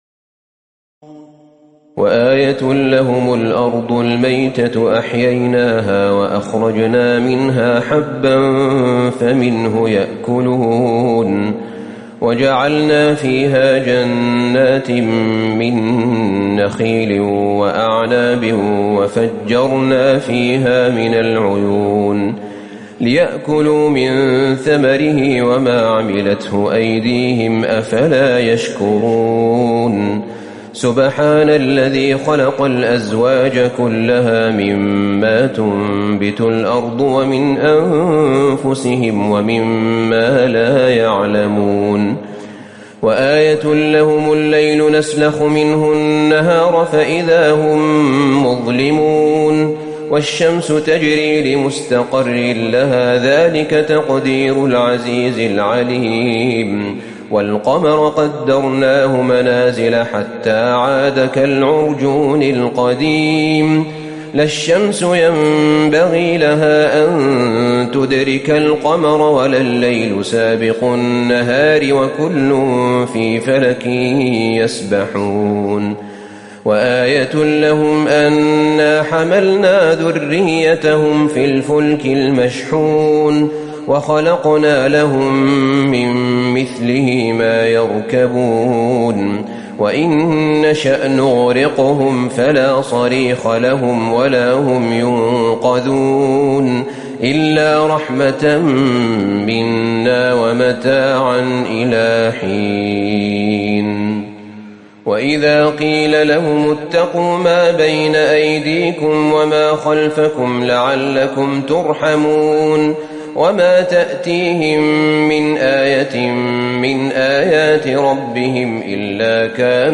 تراويح ليلة 22 رمضان 1438هـ من سور يس (33-83) والصافات كاملة Taraweeh 22 st night Ramadan 1438H from Surah Yaseen and As-Saaffaat > تراويح الحرم النبوي عام 1438 🕌 > التراويح - تلاوات الحرمين